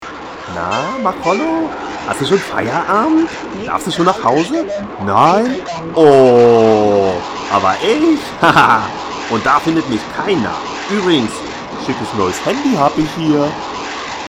Diesmal gelang es Machollo, das Gespräch aufzuzeichnen.
Telefonaufnahme